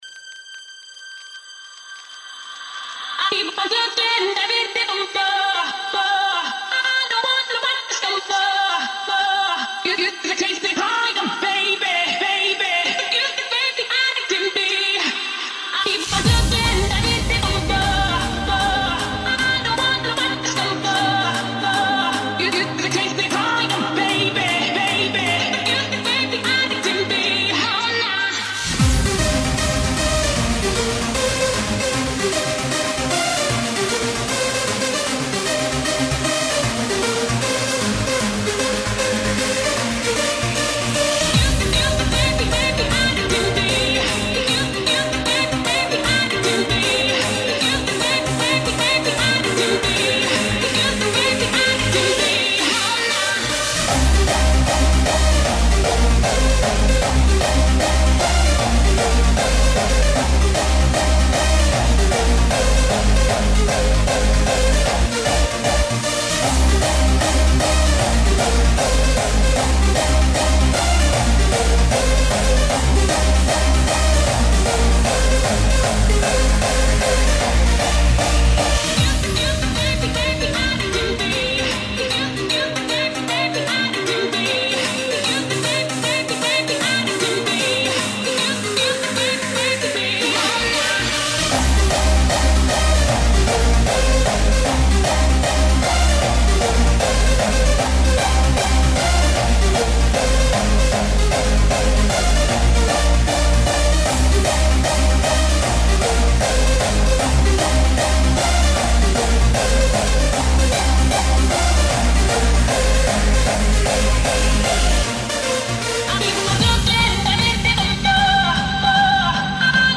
Confuzzled 2015 Live Set